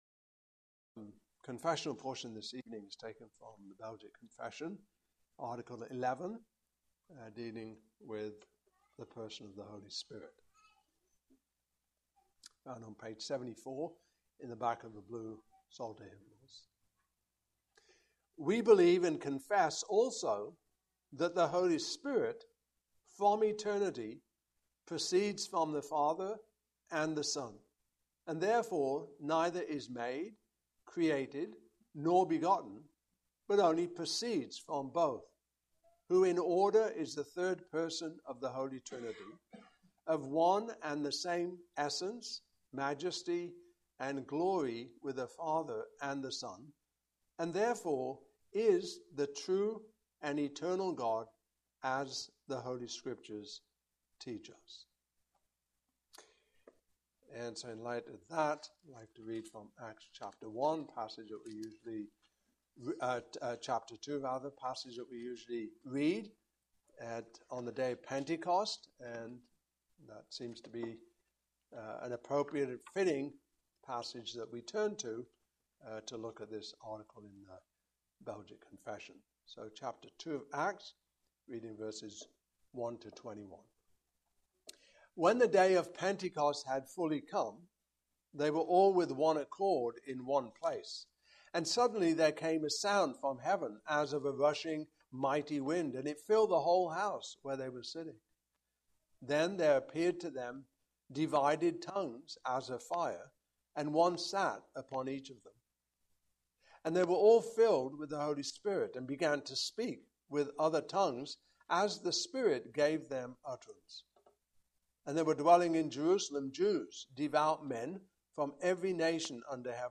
Passage: Acts 2:1-21 Service Type: Evening Service Topics